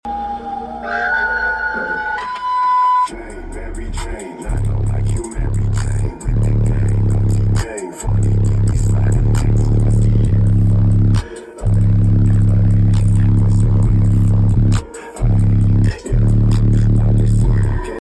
Der neue Deaf Bonce 3015r geht ganz gut an 8kw